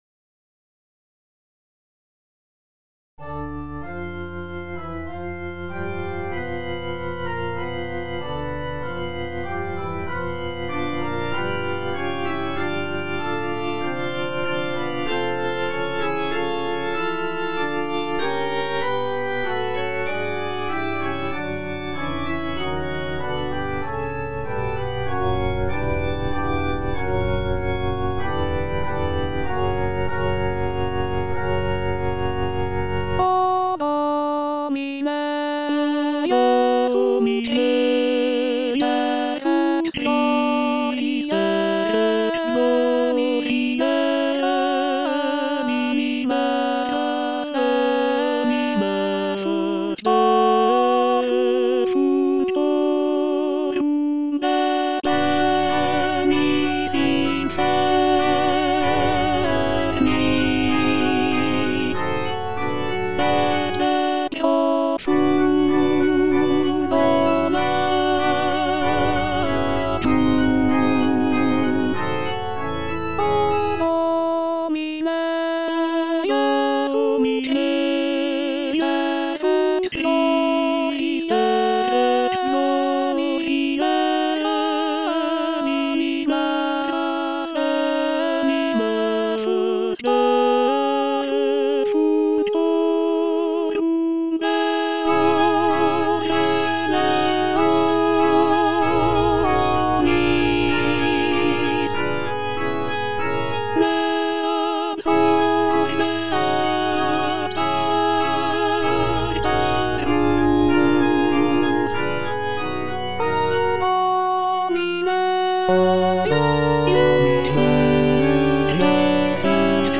G. FAURÉ - REQUIEM OP.48 (VERSION WITH A SMALLER ORCHESTRA) Offertoire - Soprano (Emphasised voice and other voices) Ads stop: